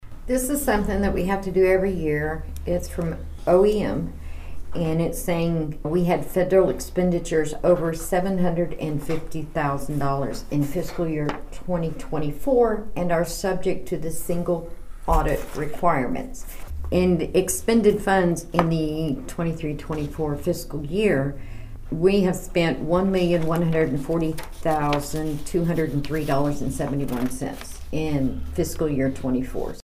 The Nowata County Board of Commissioners on Monday accepted a Notification of Single Audit Requirement from Oklahoma Management, Nowata County Clerk Kay Spurgeon explains what that means.